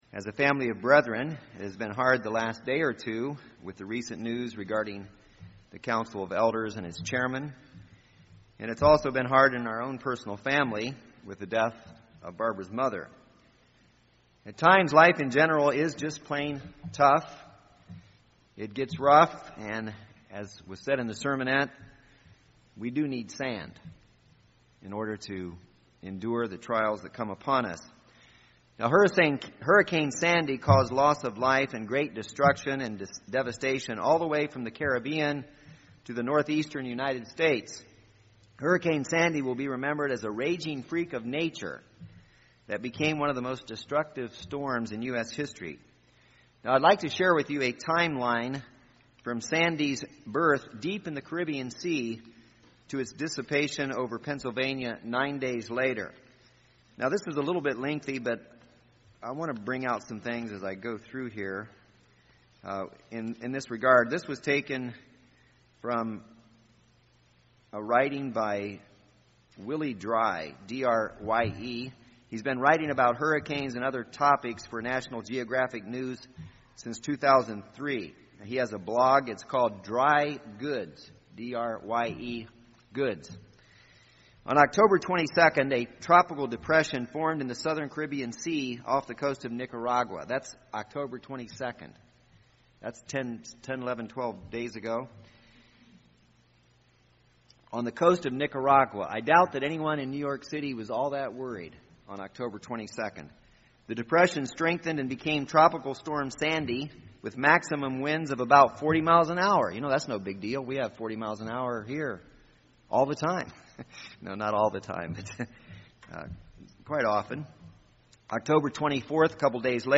In this sermon we will discuss three keys or strategies for weathering life’s storms.